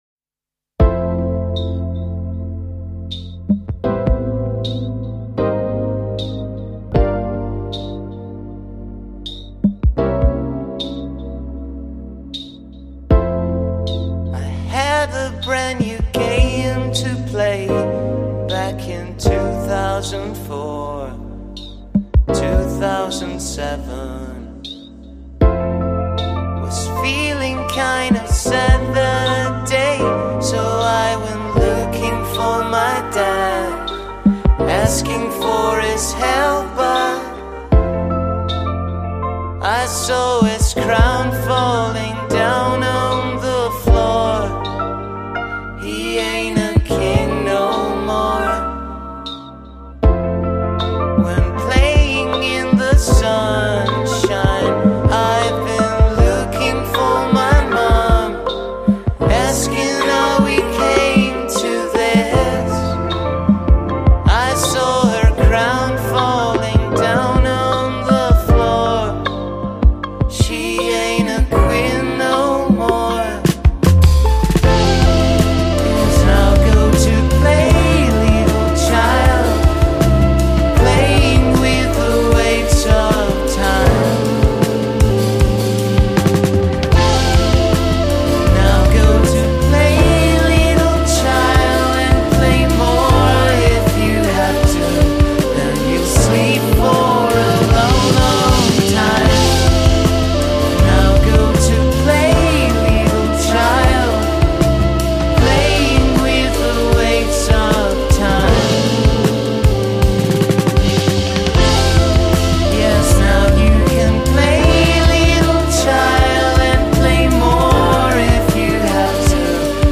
è un cantautore nato a Firenze.